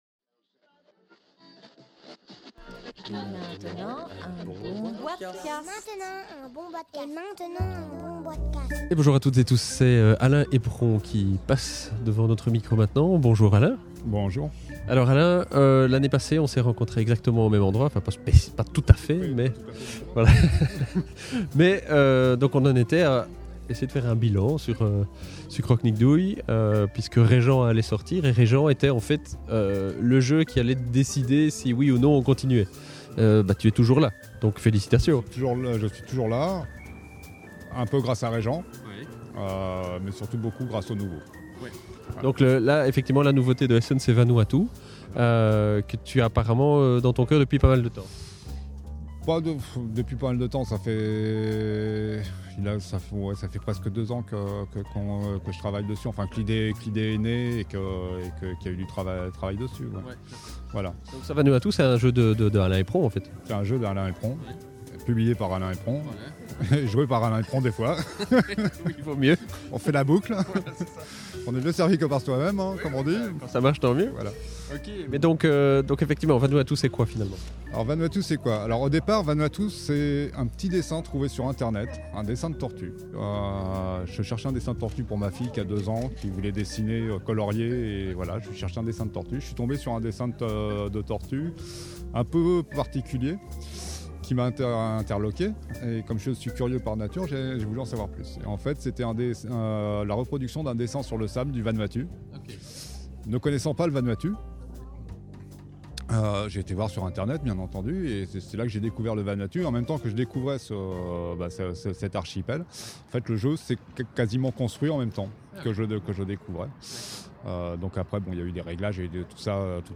(enregistré au Salon international du Jeu de Société de Essen – Octobre 2011)